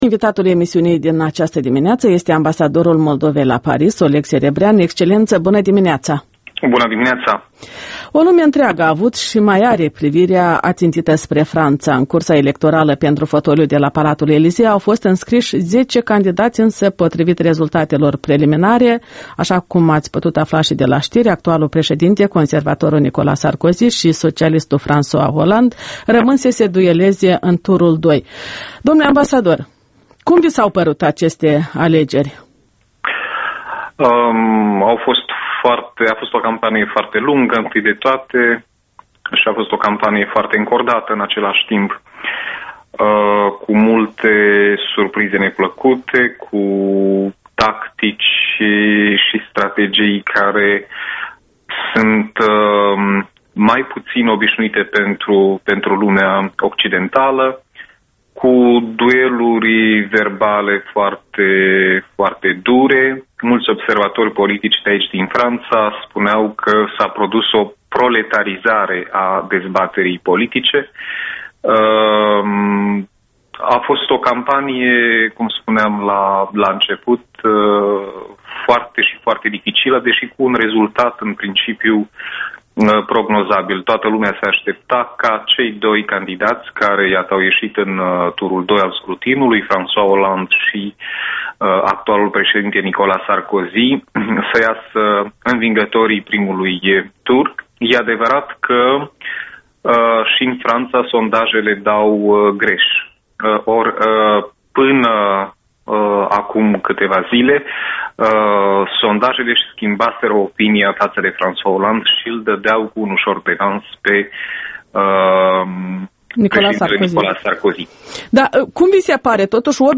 Interviul dimineții la EL: cu Oleg Serebrean, ambasadorul R. Moldova în Franța